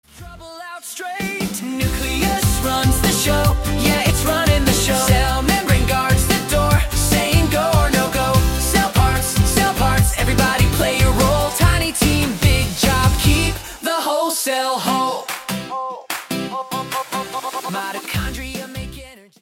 STYLE: Upbeat pop with memorable verses.